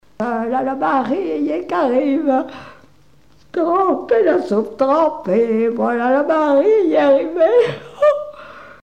gestuel : à marcher
circonstance : fiançaille, noce
Pièce musicale inédite